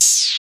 OPENHAT (SLIDE).wav